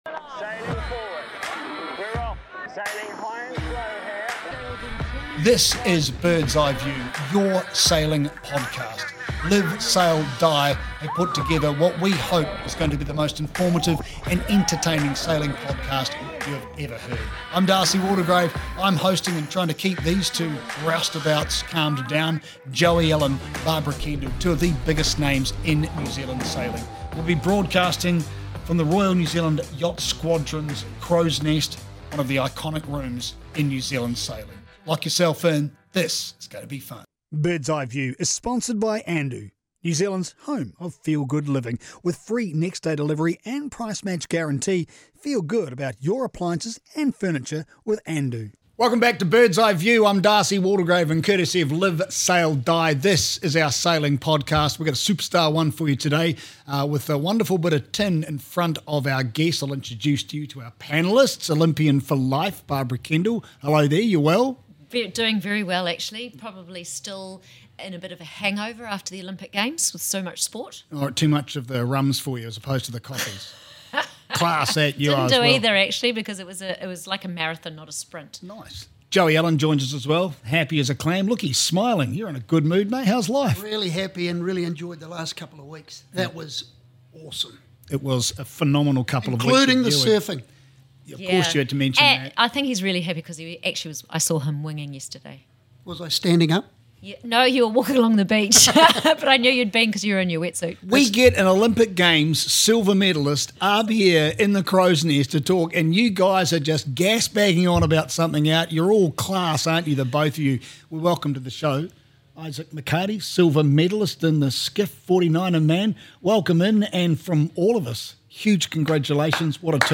Fresh from his first Olympic Games, 49er helm and all round nice guy Isaac McHardie joined us in our studio in the Crows Nest at the Royal New Zealand Yacht Squadron to talk about that moment when he and Will (McKenzie) realised they were medalists, and how it all began back in the Optimist and P Class.